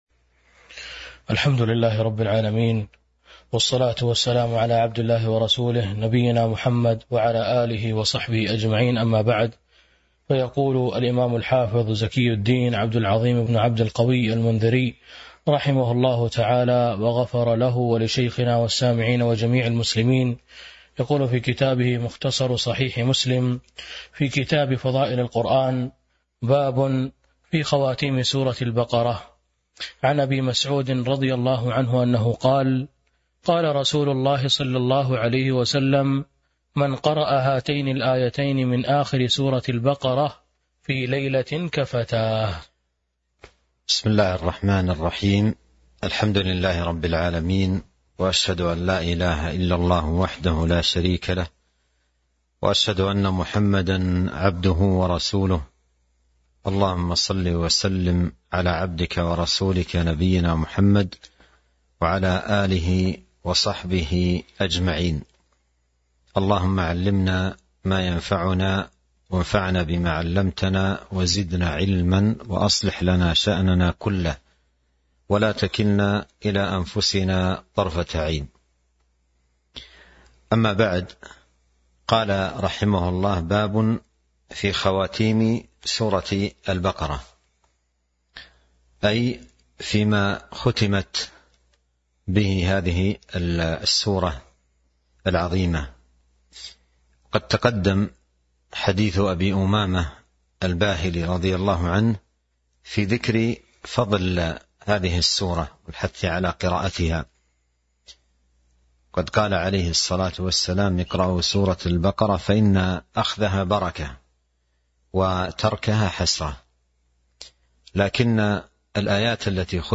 تاريخ النشر ٤ رمضان ١٤٤٢ هـ المكان: المسجد النبوي الشيخ